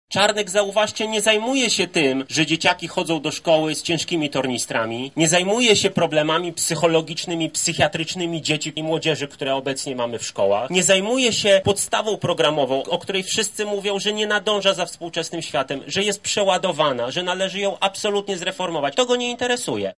• mówi poseł Michał Krawczyk z Koalicji Obywatelskiej.